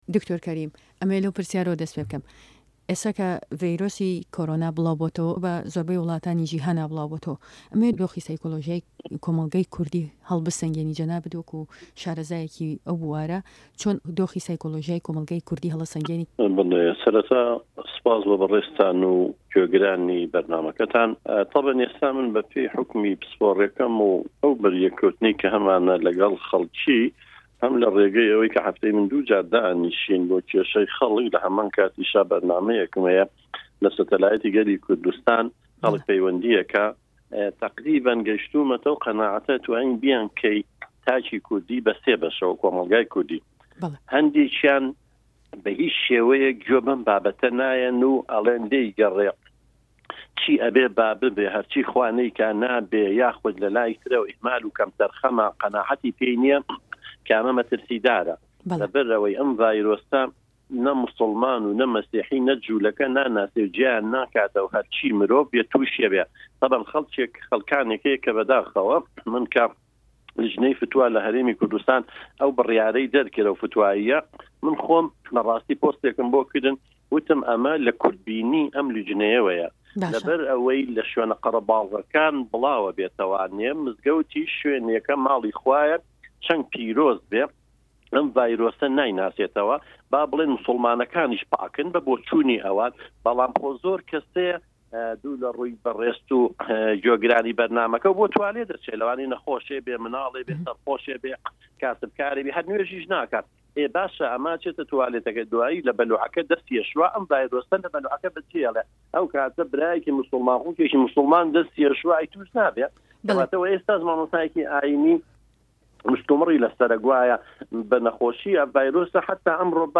ده‌قی وتووێژه‌كه‌ی